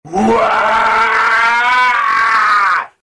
main_scream.mp3